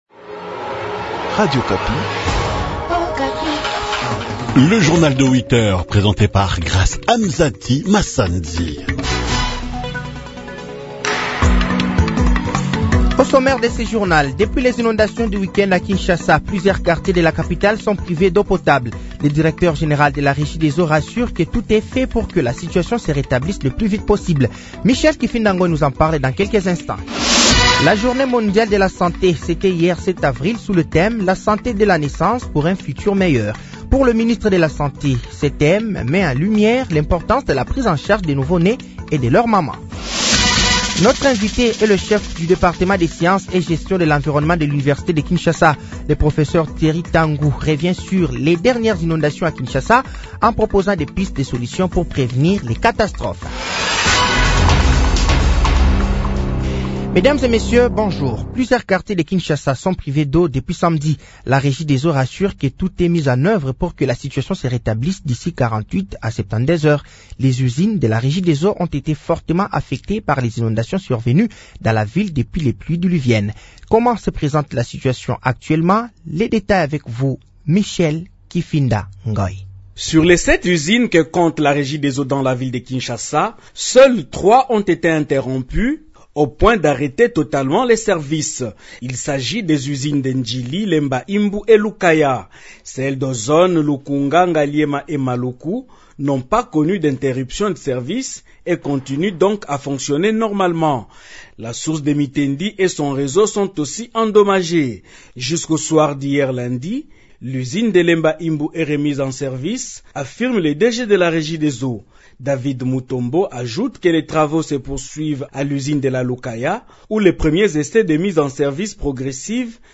Journal français de 8h de ce mardi 08 avril 2025